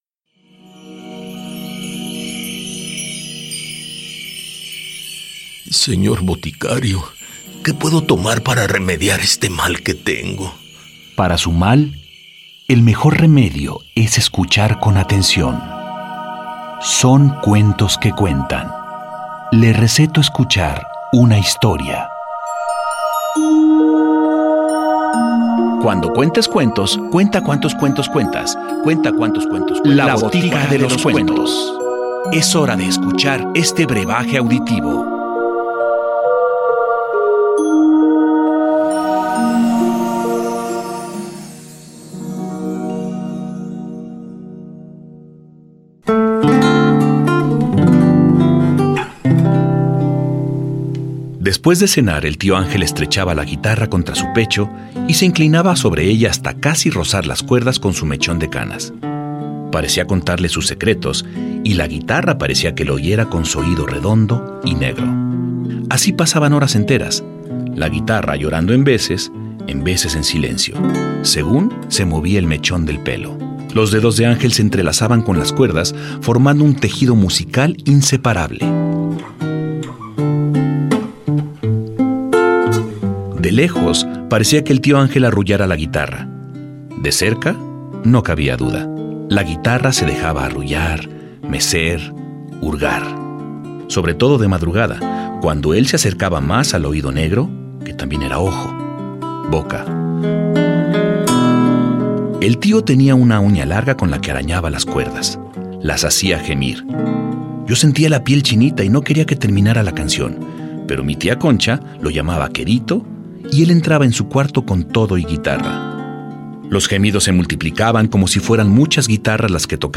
La botica de los cuentos presenta "Ojo De Guitarra", un cuento de Martha Cerda.
04 CUENTO OJO DE GUITARRA ENTRADA Y SALIDA.mp3